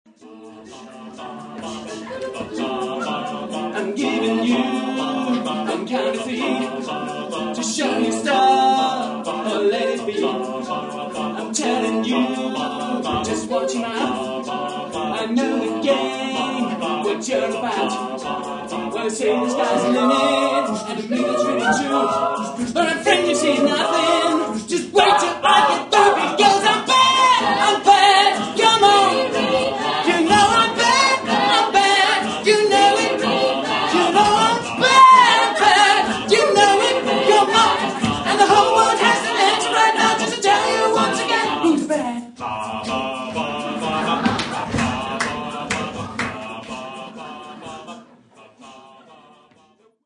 Junge Menschen singen von einer Zeit, von der sie keine Ahnung haben